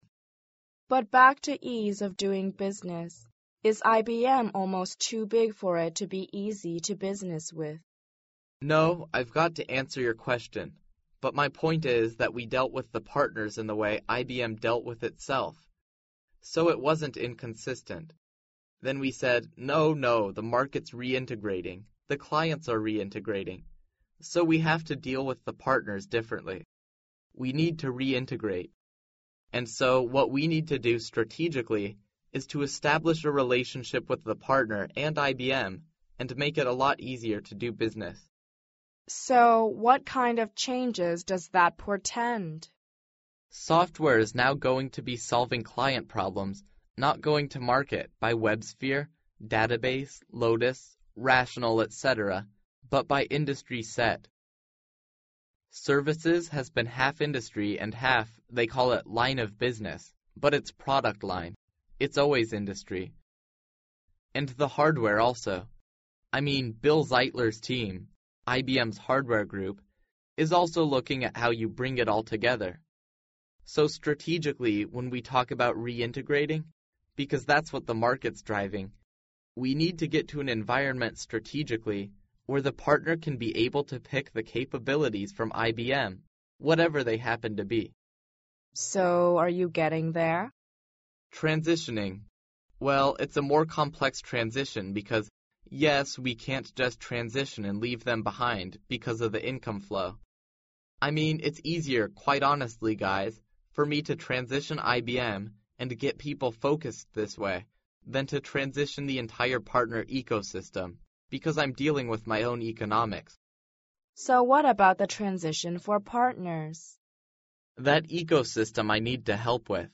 世界500强CEO访谈 第26期:IBM山姆彭明盛 营销战略和整合有关(2) 听力文件下载—在线英语听力室